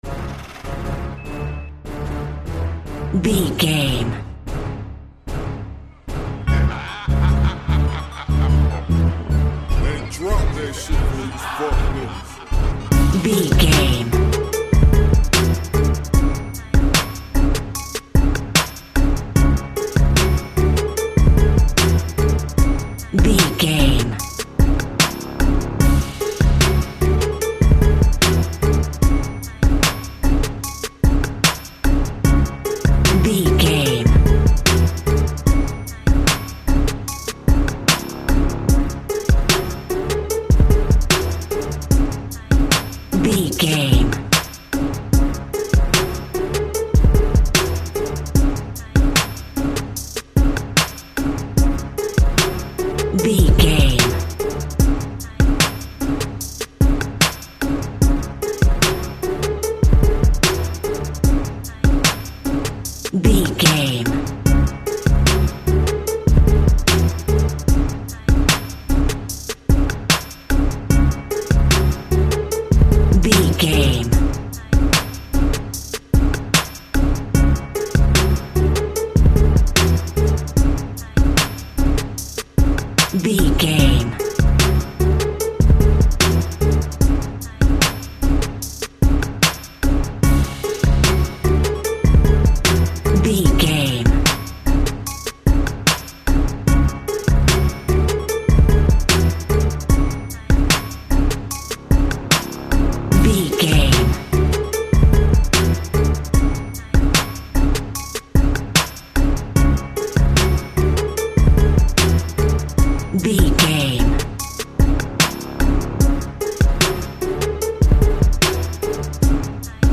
Huge Hip Hop Strings. A great piece of royalty free music
Epic / Action
Aeolian/Minor
F#
chilled
laid back
Deep
hip hop drums
hip hop synths
piano
hip hop pads